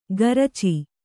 ♪ garaci